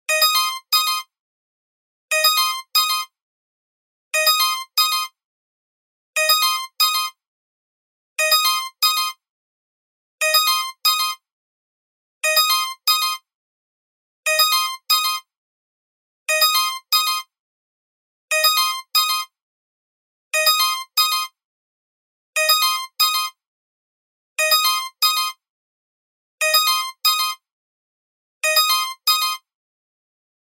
シンプルな着信音。